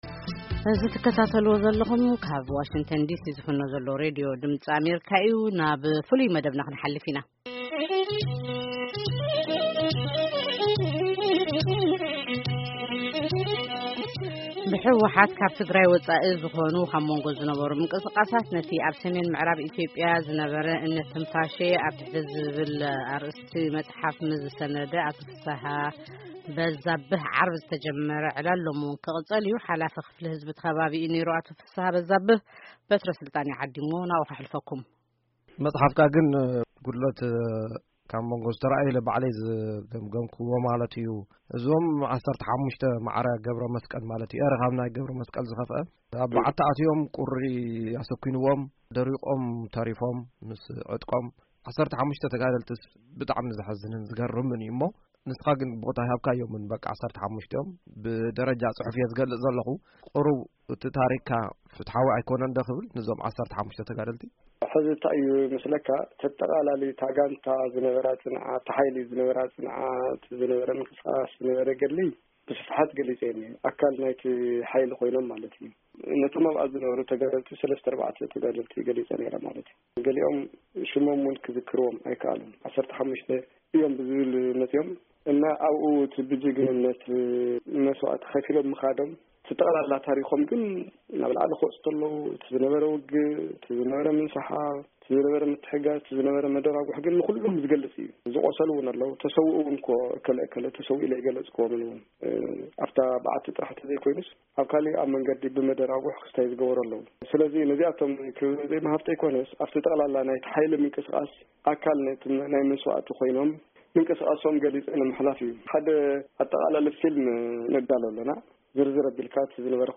ቃለ መጠይቅ